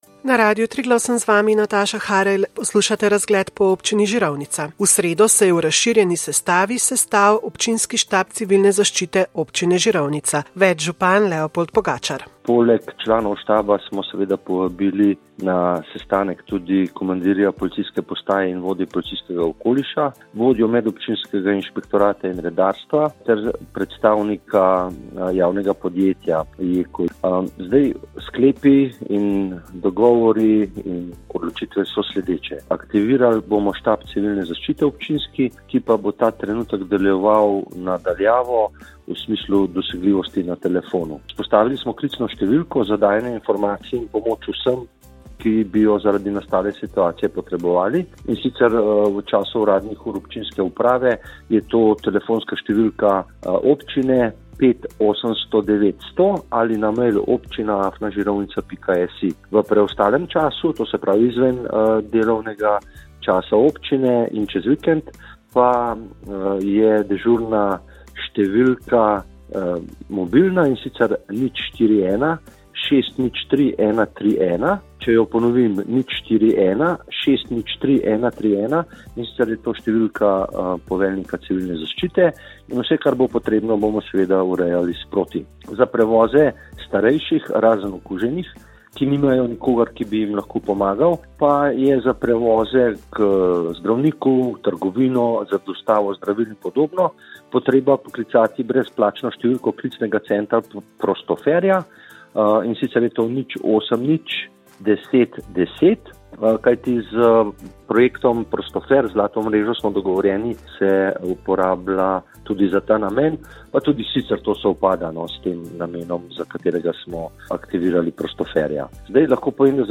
Izjava župana o ukrepih Štaba civilne zaščite Občine Žirovnica in upoštevanju ukrepov povezanih s COVID-19
Izjava, ki je bila posredovana na radiu Triglav